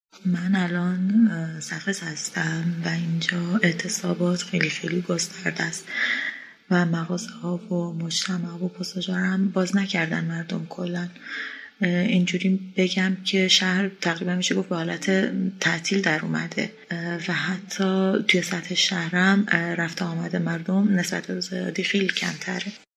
گزارش اعتصاب بازاریان در سقز